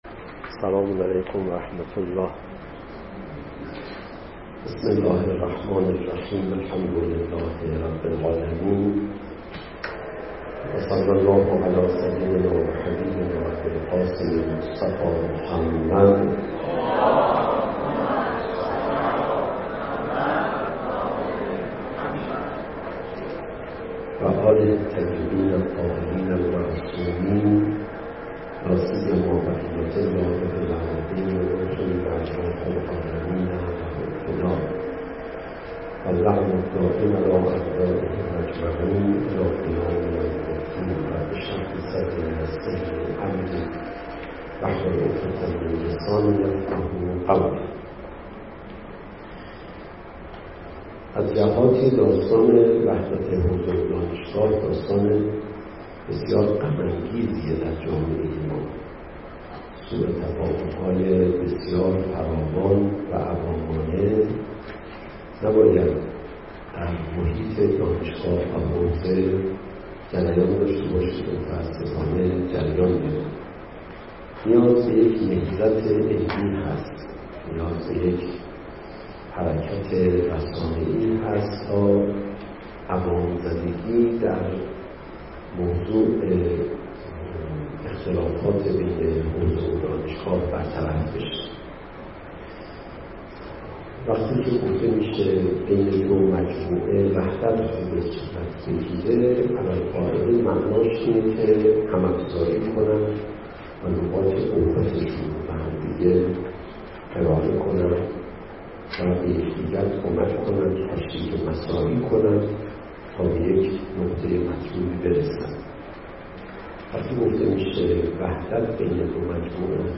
به مناسبت روز وحدت حوزه و دانشگاه (بیان نقاط قوت و ضعف دانشگاه و حوزه) با حضور استاد علیرضا پناهیان به همت انجمن اسلامی دانشجویان دانشگاه علامه طباطبایی (عضو دفتر تحکیم وحدت) با همکاری دفتر کانون های حوزه علمیه دارالحکمه دوشنبه ۲۷ آذر در سالن دانشکده روانشناسی و علوم تربیتی برگزار شد.
در بخش پایانی مراسم دانشجویان پرسش های خود درباره چگونگی، چرایی و چیستی موضوع، پیشنهادها و چالش های پیش روی وحدت حوزه و دانشگاه را مطرح کردند. صوت سخنرانی حجت الاسلام و المسلمین علیرضا پناهیان